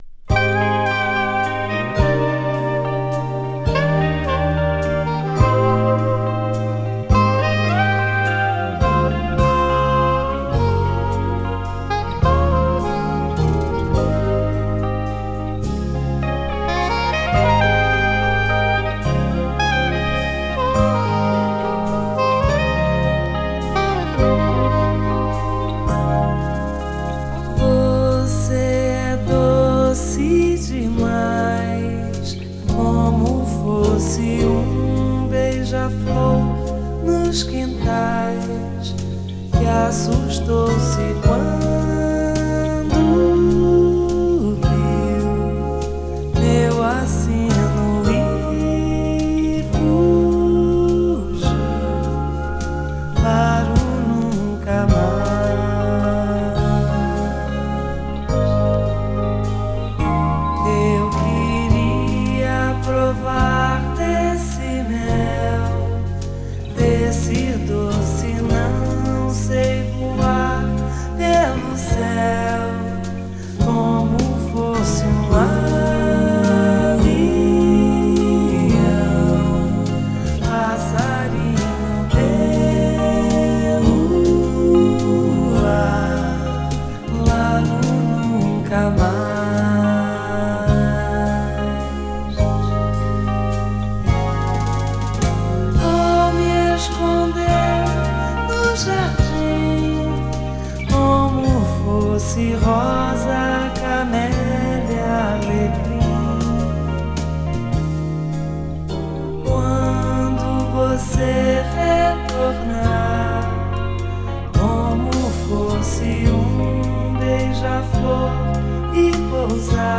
ブラジル出身のシンガー